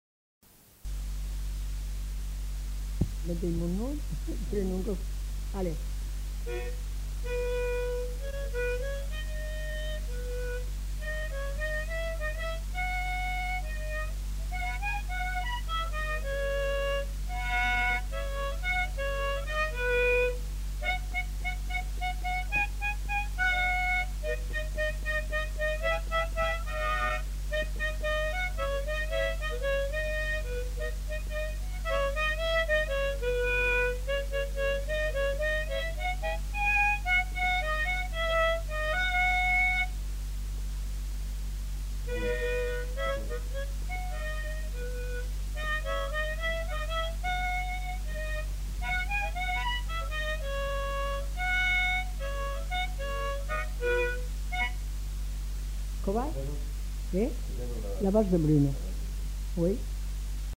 Aire culturelle : Haut-Agenais
Lieu : Cancon
Genre : morceau instrumental
Instrument de musique : harmonica
Danse : valse